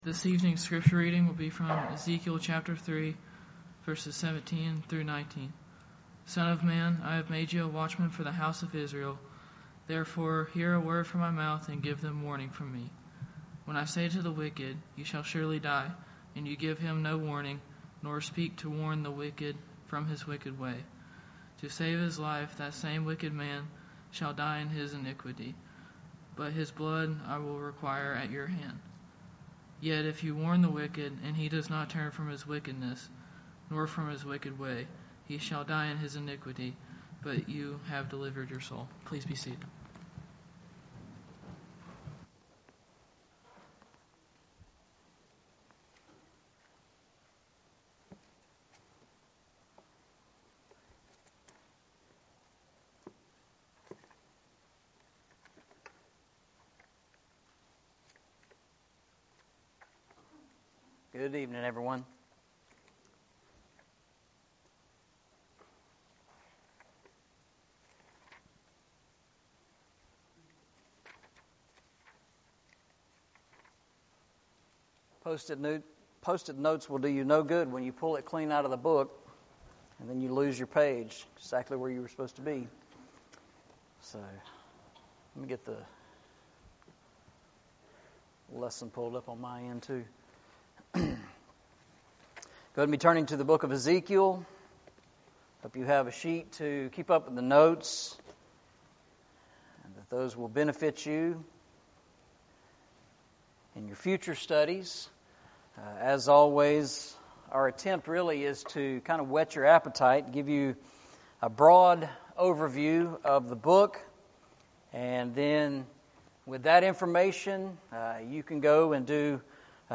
Eastside Sermons Passage: Ezekiel 3:17-19 Service Type: Sunday Evening « Can You Carry the Cross?